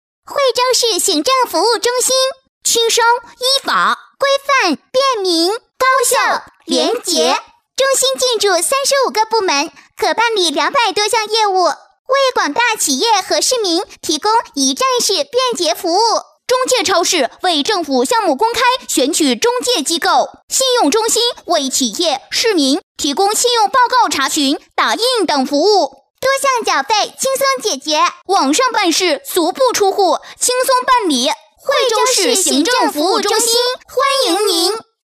女国语132
【专题】惠州行政中心